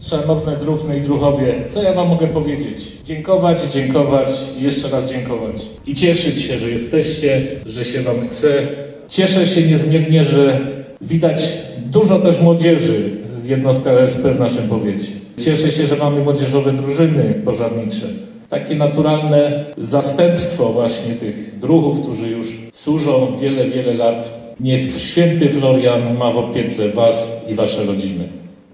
Tak o jednostkach OSP mówił dzisiaj w Rajczy, w czasie powiatowych obchodów „Święta Strażaka”, senator RP Andrzej Kalata, który jest jednocześnie prezesem Zarządu Oddziału Powiatowego Związku Ochotniczych Straży Pożarnych RP w Żywcu.